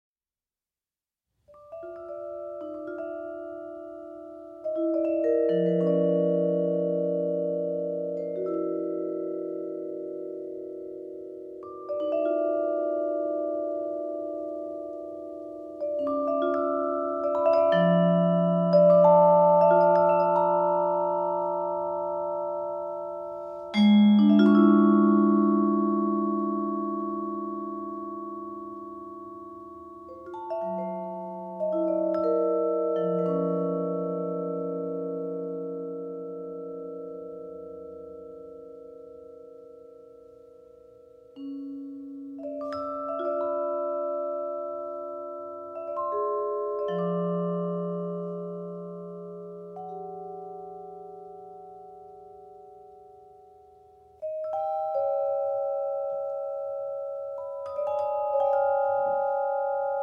Percussion